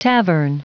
Prononciation du mot tavern en anglais (fichier audio)
Prononciation du mot : tavern